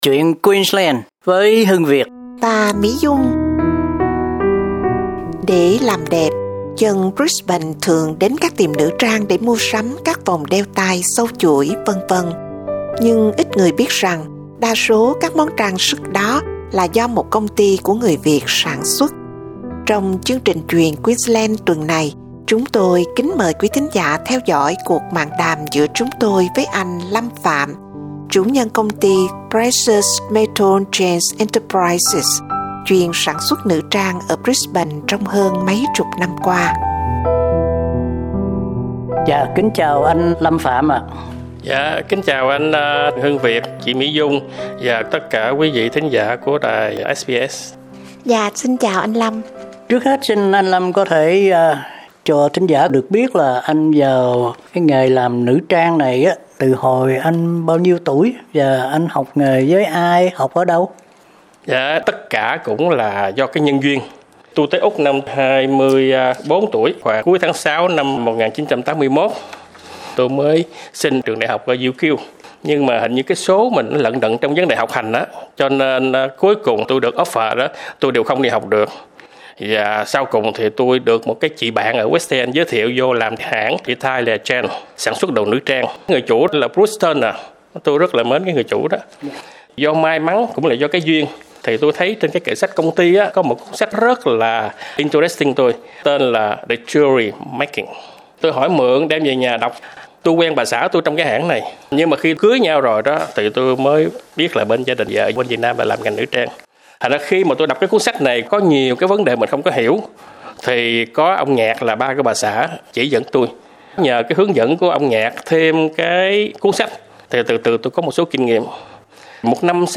cuộc mạn đàm